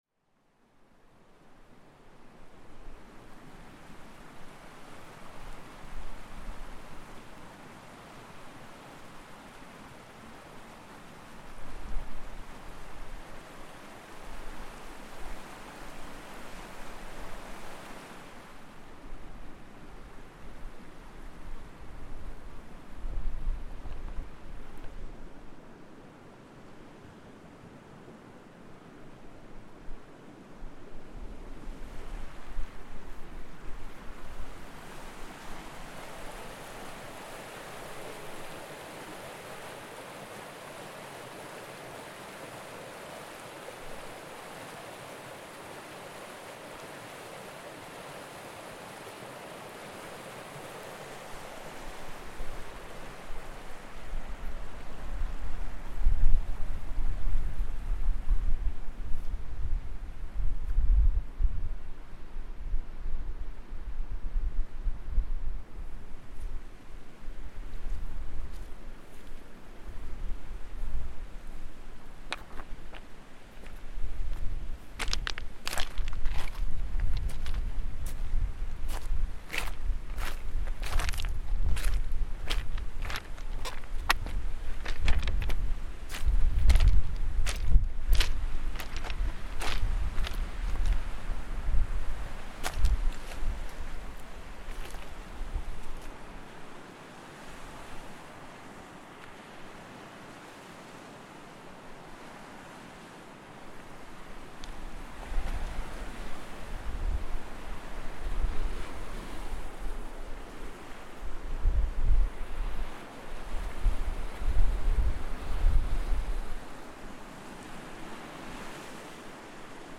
Field recording from Klifavik fjord, Iceland - sea water, stream, wind and footsteps.